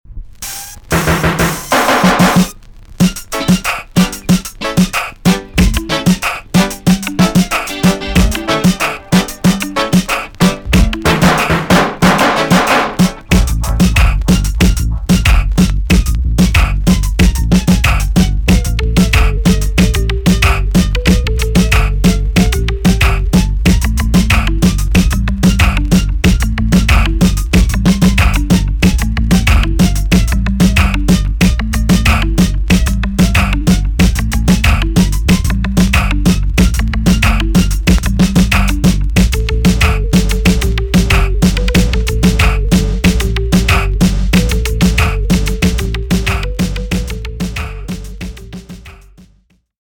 TOP >80'S 90'S DANCEHALL
B.SIDE Version
EX- 音はキレイです。